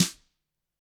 Index of /90_sSampleCDs/ILIO - Double Platinum Drums 1/CD4/Partition C/GRETCHBRSNRD